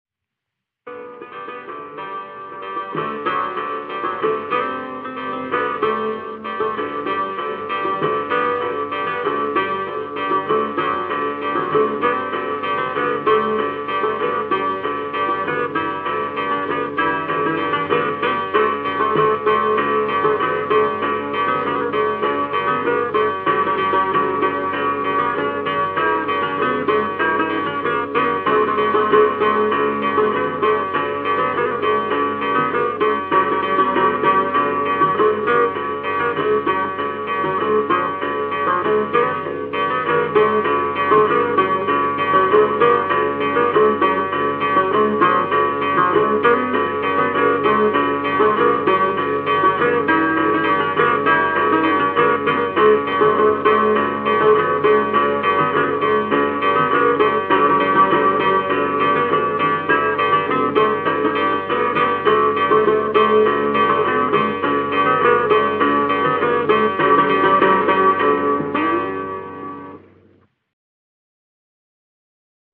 Samba